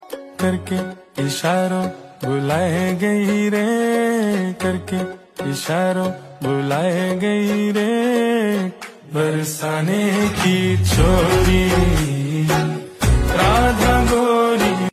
Chant Hare Krishna Maha Manta Sound Effects Free Download